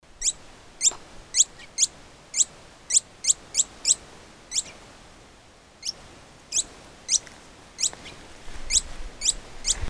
Canastero Coludo (Asthenes pyrrholeuca)
Nombre en inglés: Sharp-billed Canastero
Fase de la vida: Adulto
Localidad o área protegida: Junin de los Andes
Condición: Silvestre
Certeza: Observada, Vocalización Grabada
CanasteroColudo.mp3